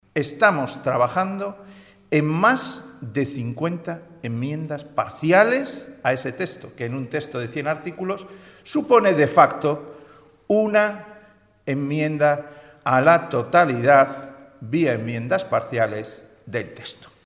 Ver declaraciones de Pedro Hernando, portavoz parlamentario del Partido Regionalista de Cantabria.
Pedro Hernando en un momento de la rueda de prensa que ha ofrecido hoy